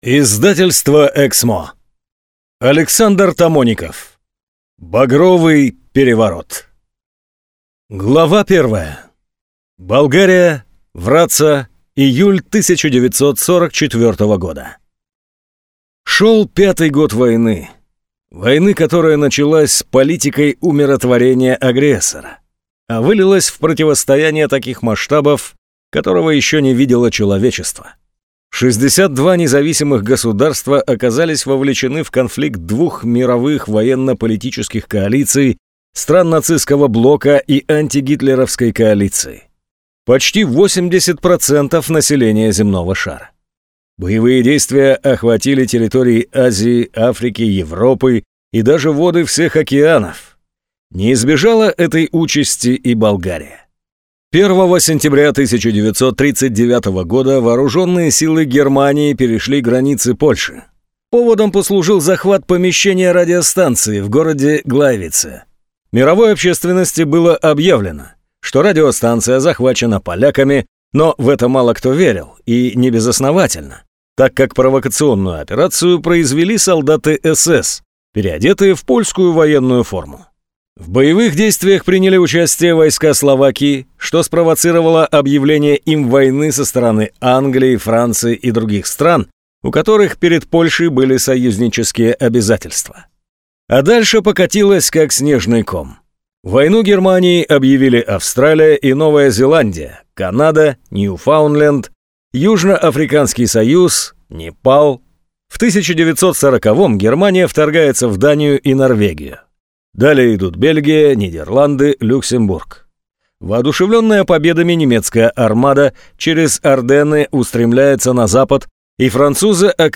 Аудиокнига Багровый переворот | Библиотека аудиокниг